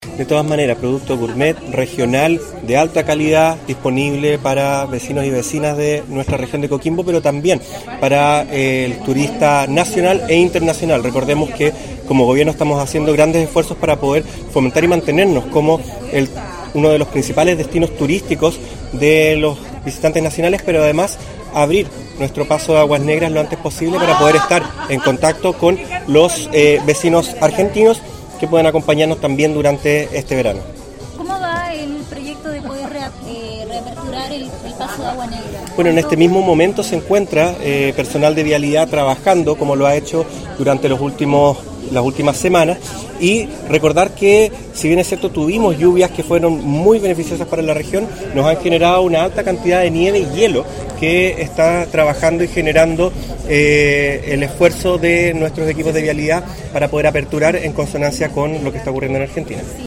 AUDIO : Delegado Presidencial Ruben Quezada